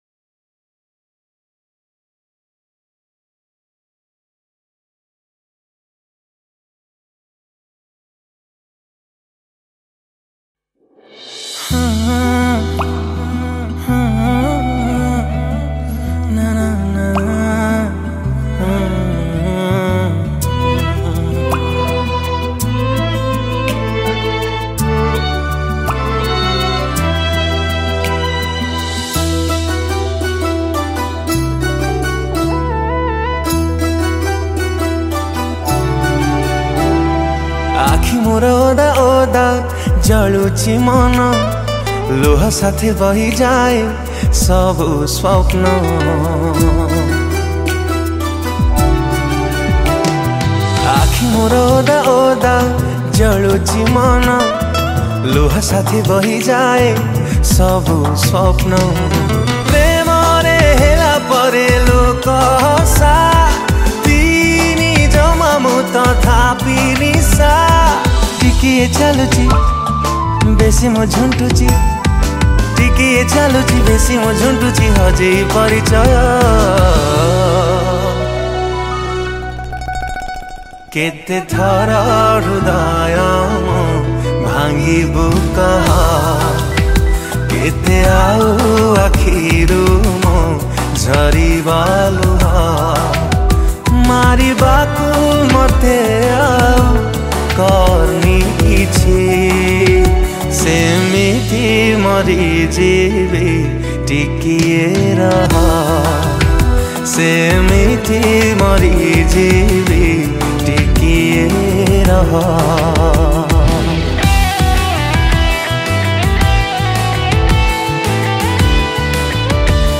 Back Vocal
Keyboard Programmed
Violine
Live Guitar
Acoustic Guitar and Electric Guitar
Drums